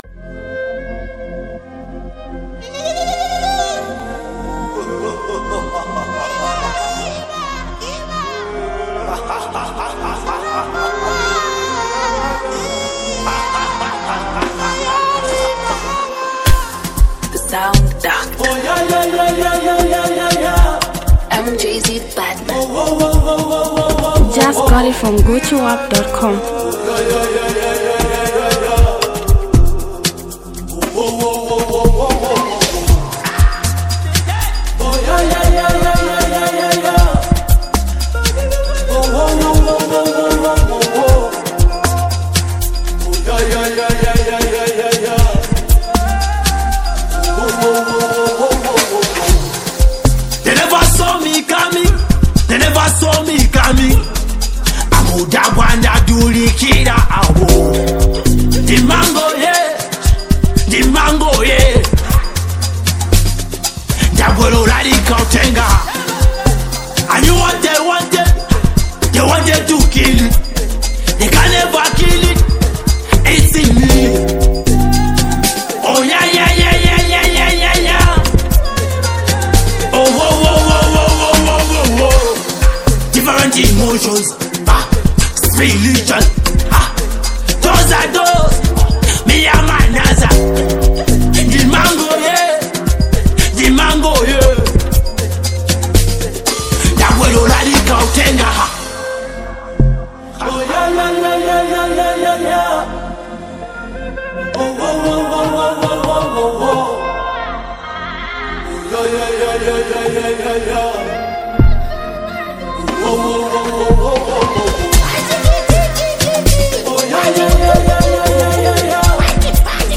Zambian Mp3 Music